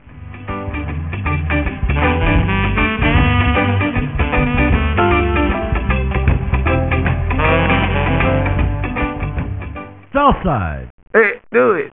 Frontline Rhythm & Blues
All sound clips are 8 bit mono 6000 Hz .WAV